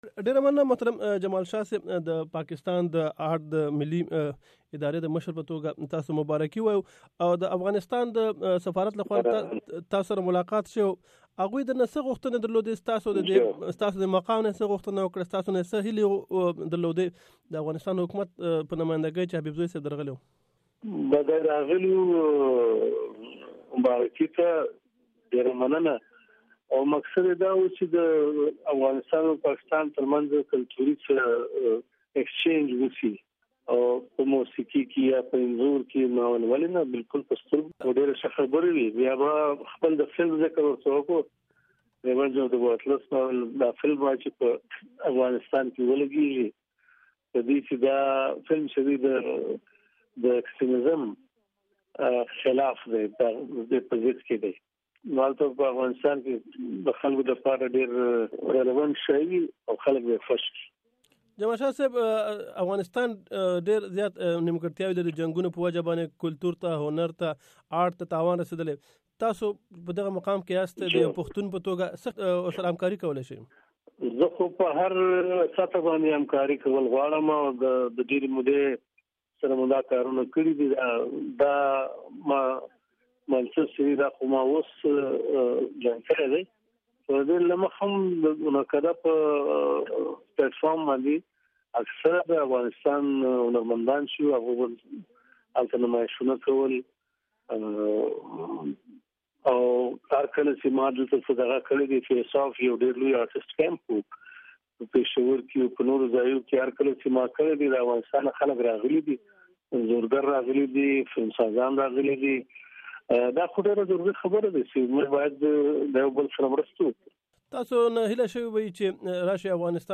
نامتو پښتون هنرمند ښاغلي جمال شاه سره مرکه